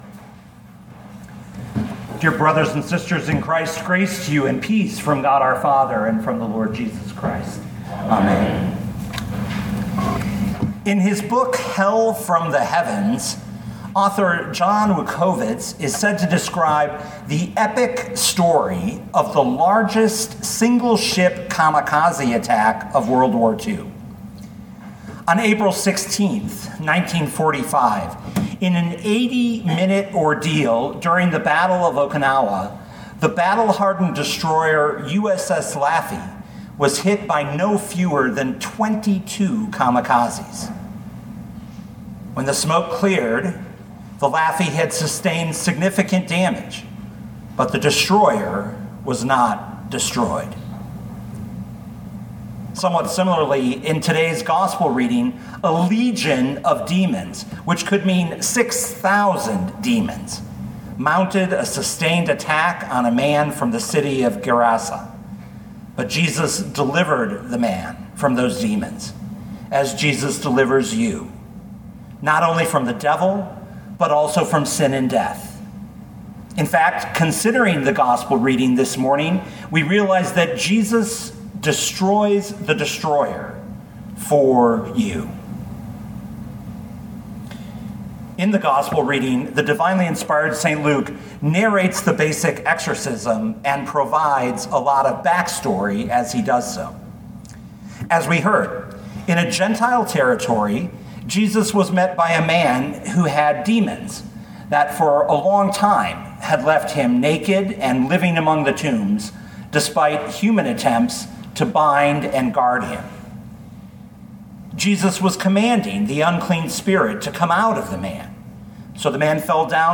2022 Luke 8:26-39 Listen to the sermon with the player below, or, download the audio.